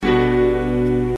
SAMPLES : Piano
piano nē 8
piano8.mp3